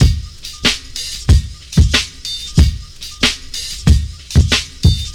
• 93 Bpm High Quality Hip-Hop Breakbeat D# Key.wav
Free breakbeat - kick tuned to the D# note. Loudest frequency: 1366Hz
93-bpm-high-quality-hip-hop-breakbeat-d-sharp-key-yN2.wav